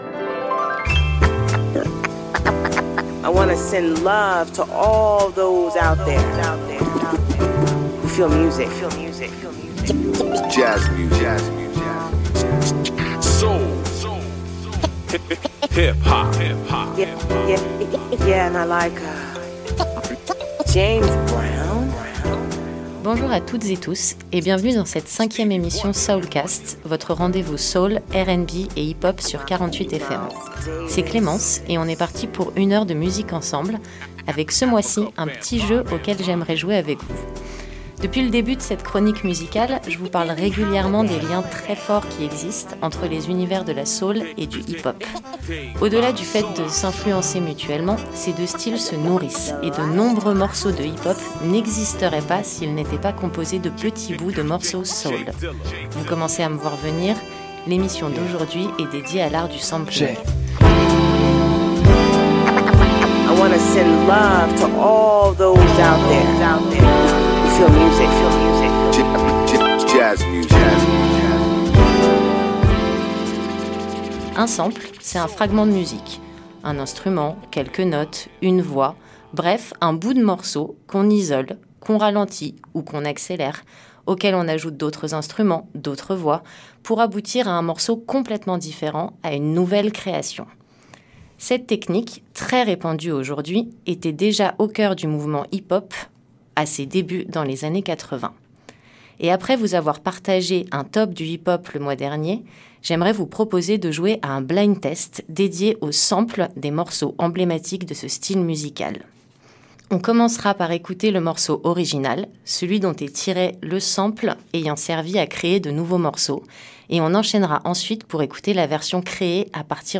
Jouez à un blind-test géant et tentez de découvrir les boucles originelles de quelques des plus grands classiques de l’histoire du rap et de du RnB !